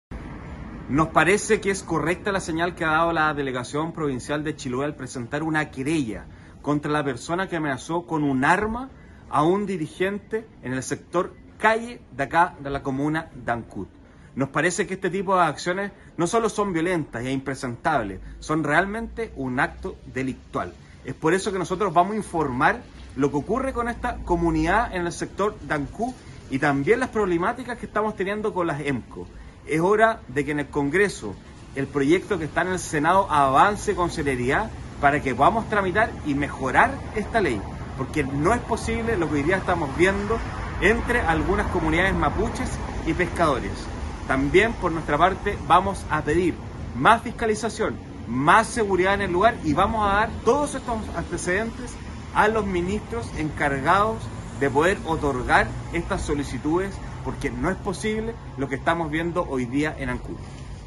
Los hechos que también han escalado hasta el ámbito parlamentario, tienen su génesis en una ley que a juicio de los perjudicados, debe ser modificada. En torno a los acontecimientos, se refirió también el diputado del Distrito 26, Alejandro Bernales.